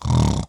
imvest-stress-test-main-quest/Snort.wav at master
Snort.wav